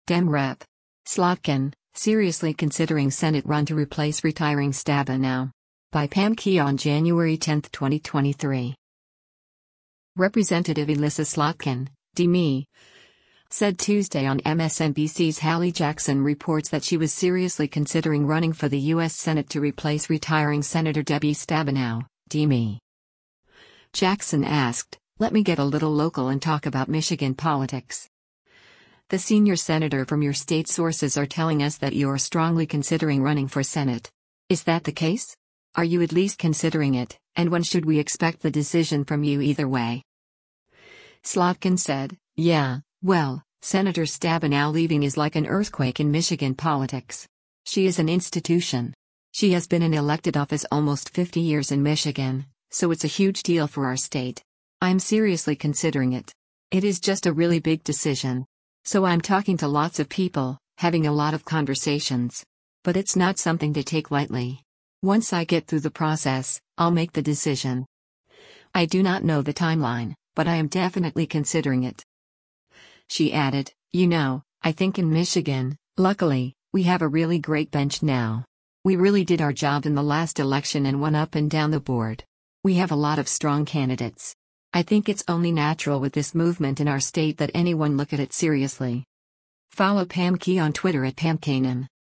Representative Elissa Slotkin (D-MI) said Tuesday on MSNBC’s “Hallie Jackson Reports” that she was “seriously considering” running for the U.S. Senate to replace retiring Sen. Debbie Stabenow (D-MI).